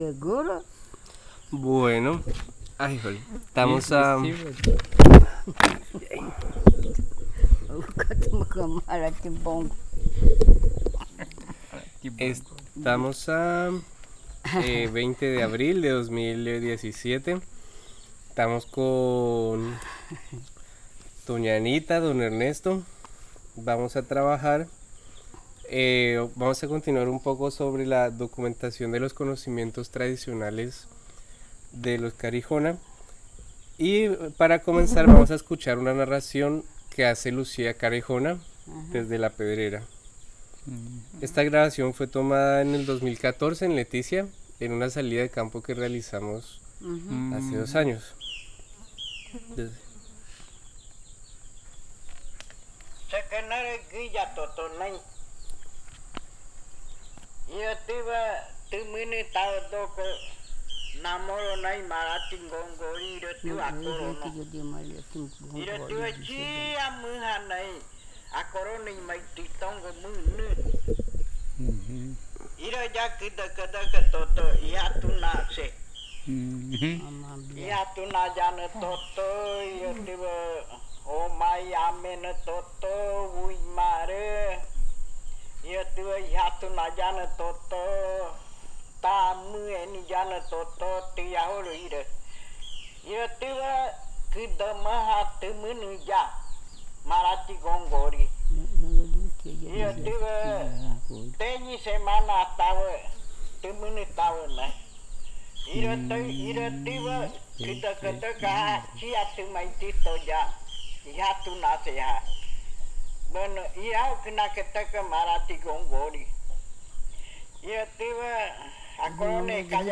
Narración Dueño de las frutas.